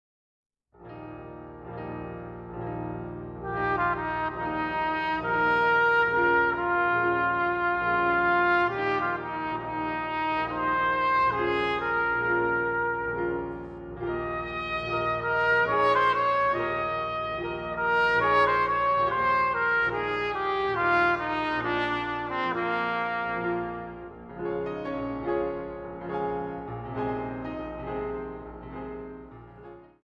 Piano
Pour Trumpette Chromatique and Piano